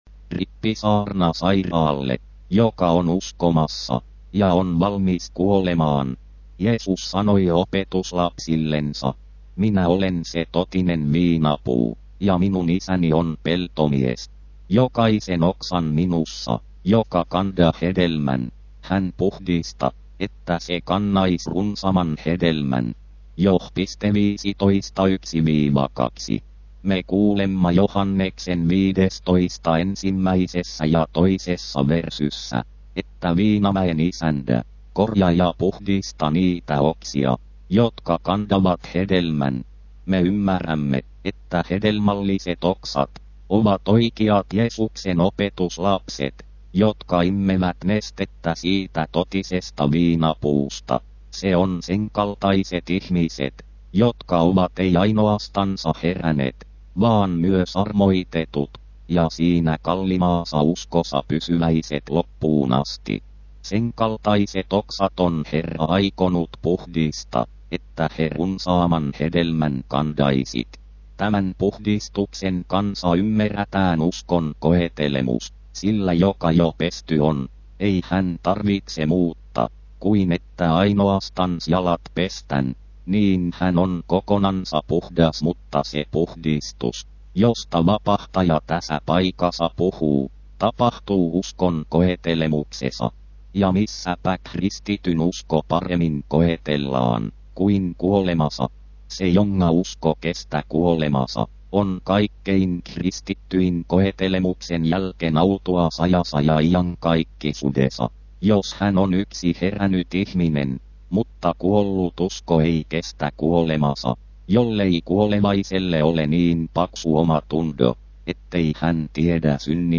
Rippisaarna: Totinen viinapuu Uleåborgs landsarkiv / Oulun Maakunta-arkisto Hela / Kokonaan 20MB Hela / Kokonaan 0,5 MB